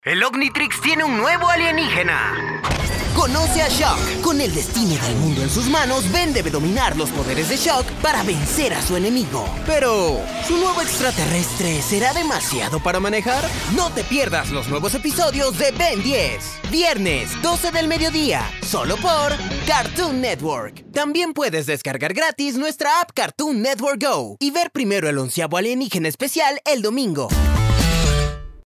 VOICE OVER TALENT
Promo Ben 10